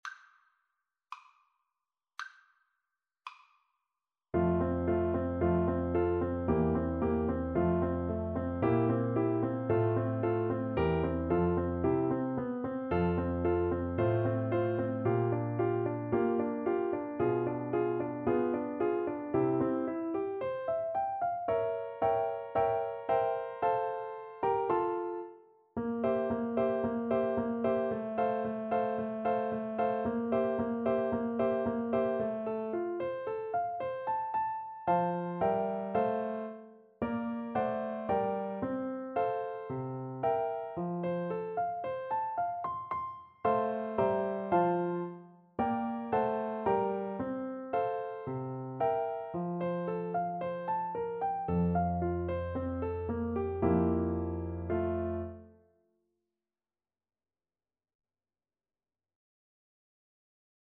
2/4 (View more 2/4 Music)
~ = 56 Affettuoso
Classical (View more Classical Flute Music)